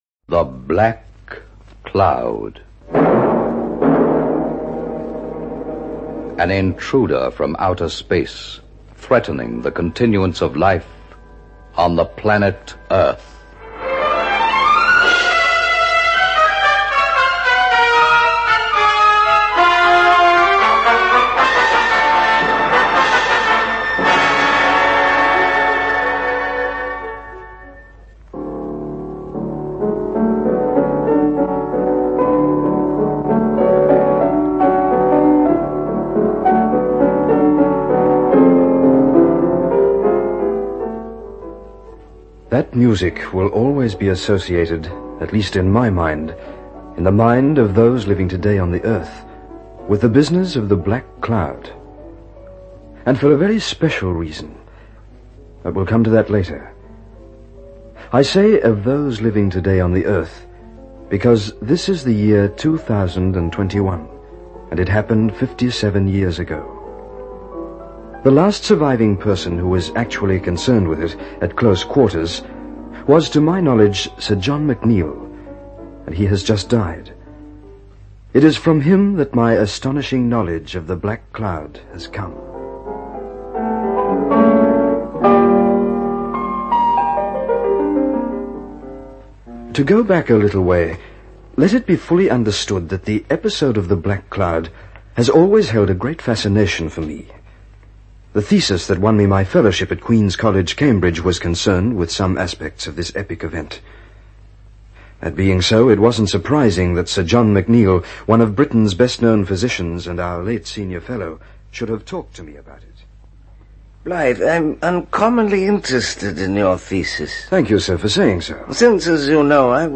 It’s not a new concept – our radio drama The Black Cloud was produced in the late 1950s.